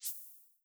LittleSwoosh2b.wav